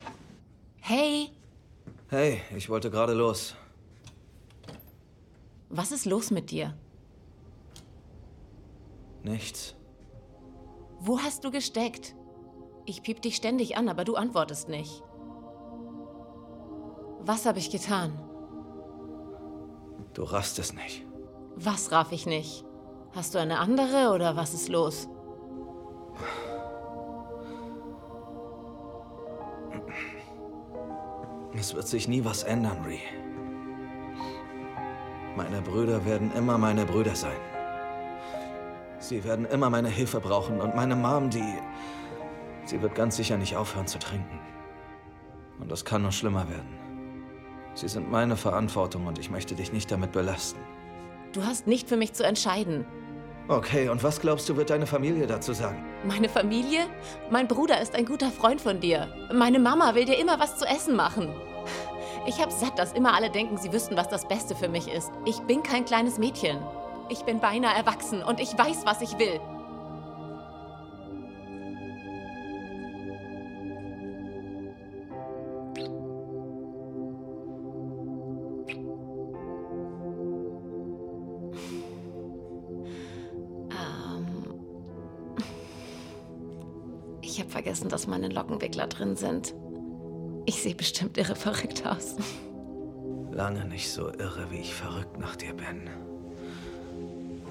dunkel, sonor, souverän, hell, fein, zart, markant, plakativ, sehr variabel
Mittel minus (25-45)
Bayrisch
Lip-Sync (Synchron)